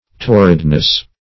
Torridness \Tor"rid*ness\, n. The quality or state of being torrid or parched.